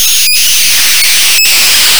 ロジスティック写像と不快音